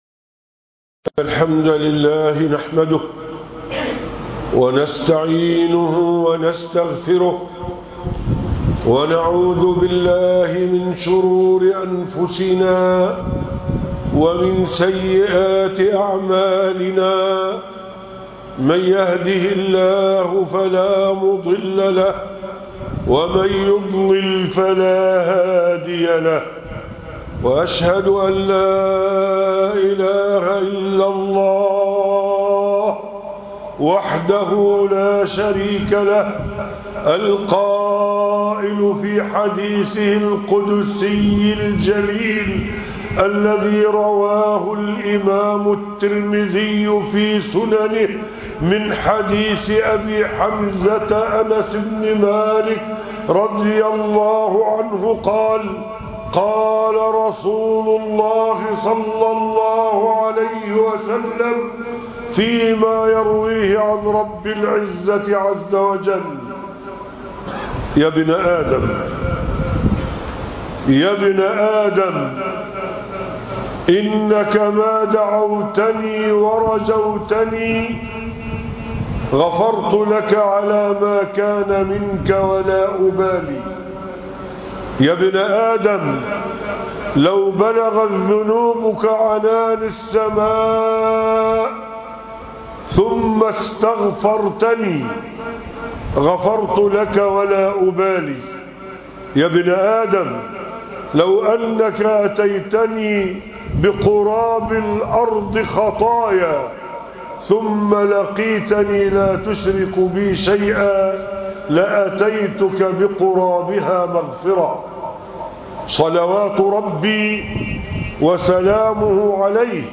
المخافة من الله - خطبة الجمعة